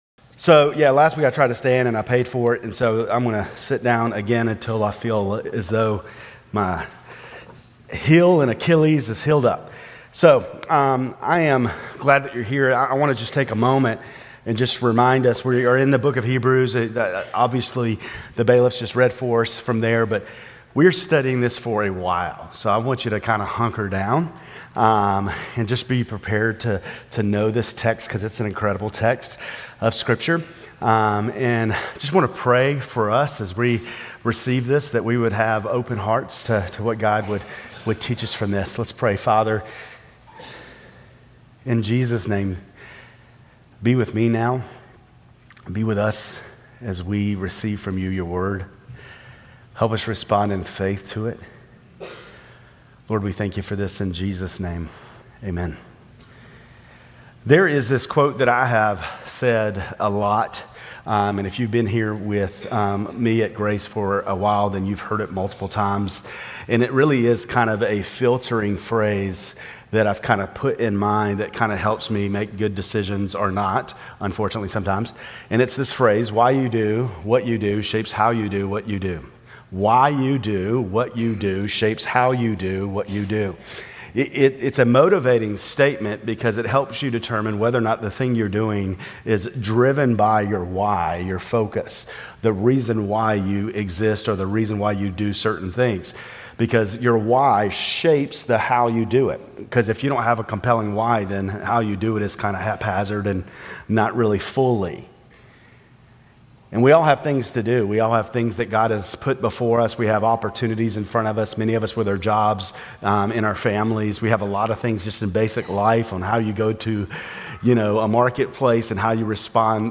Grace Community Church Lindale Campus Sermons 9_21 Lindale Campus Sep 22 2025 | 00:29:29 1x 00:00 / 00:29:29 Subscribe Share RSS Feed Share Link Embed